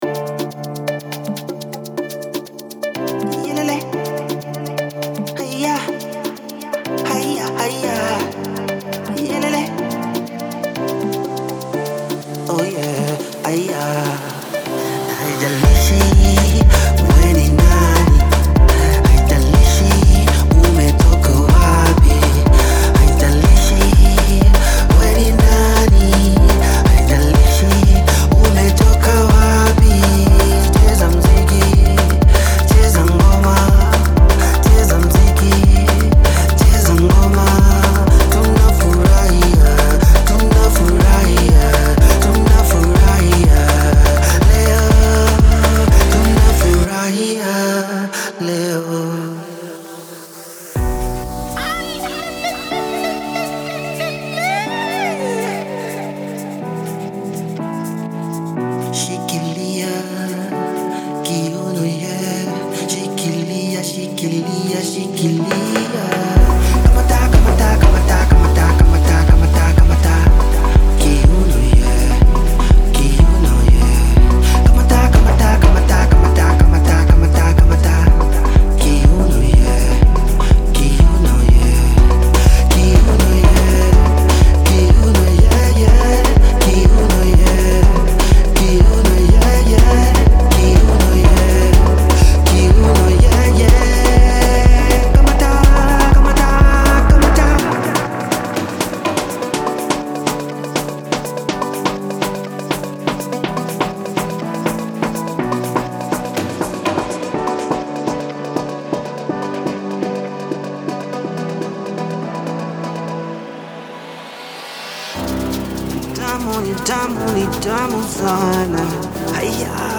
デモサウンドはコチラ↓
Genre:Afro House
120, 123 BPM
235 Wav Loops (Synths, Basses, Afro Vocals, Fx, Drums)